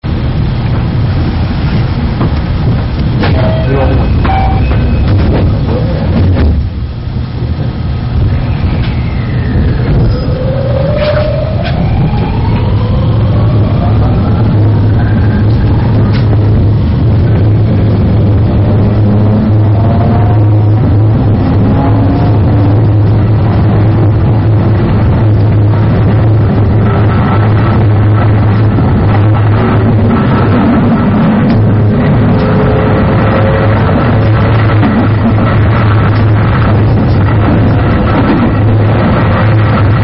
223系1000番台･2000番台 モーター音
すべて、どこかの駅の出発の４０秒間を収録しています。
ちょっと雑音が多すぎて、聴きづらいかもしれません。